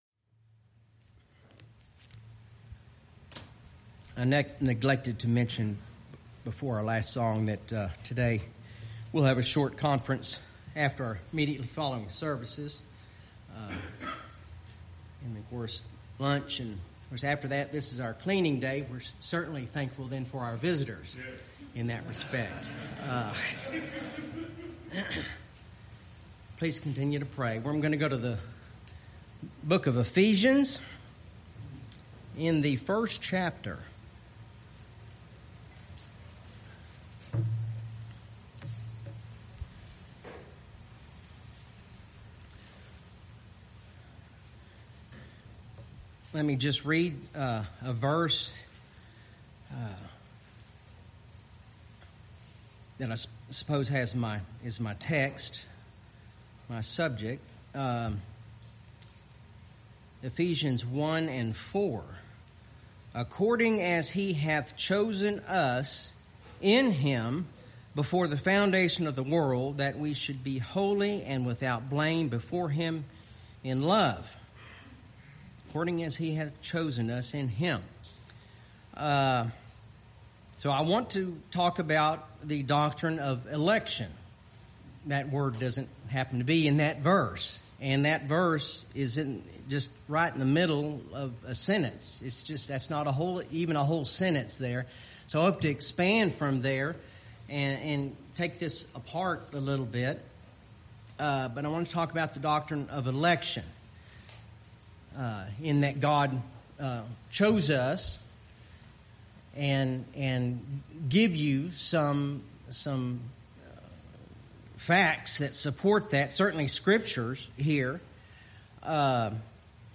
BHPBC Sermon podcast